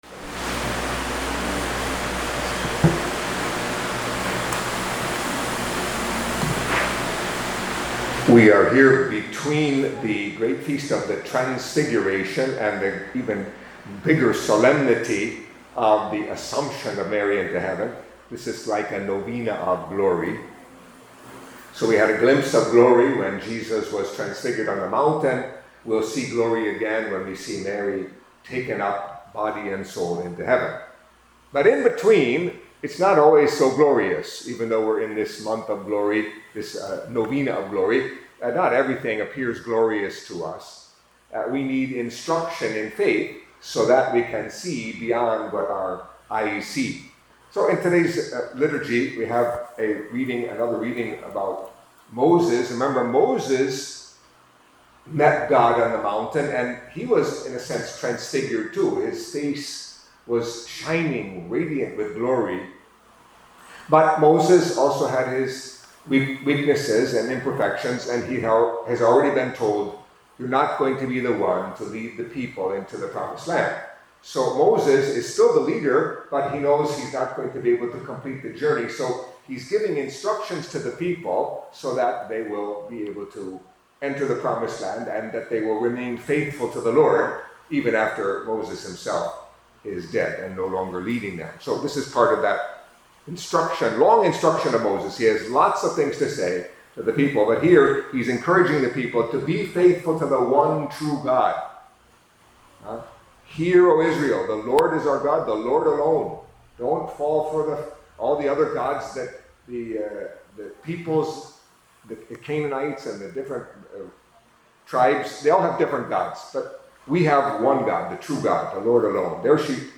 Catholic Mass homily for Saturday of the Eighteenth Week in Ordinary Time